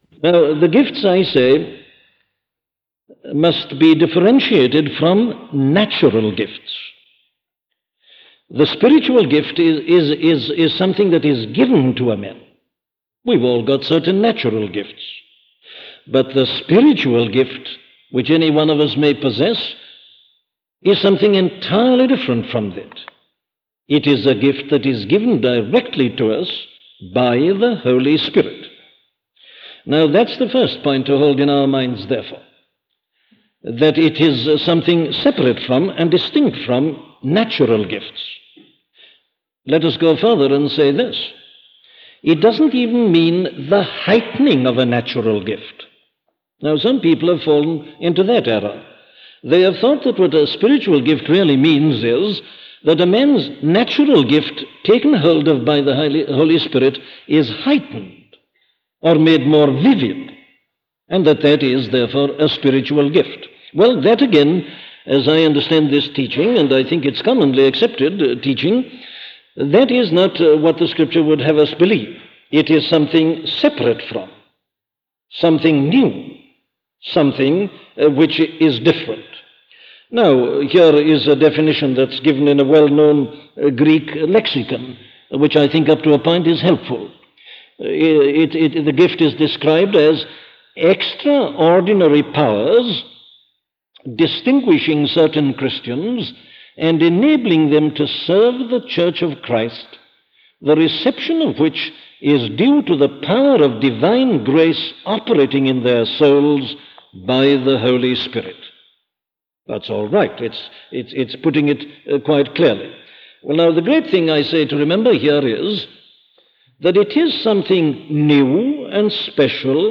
Listen to the sermon 'The Gifts of the Holy Spirit' by Dr. Martyn Lloyd-Jones
Dr. Martyn Lloyd-Jones (1899-1981) was a Welsh evangelical minister who preached and taught in the Reformed tradition.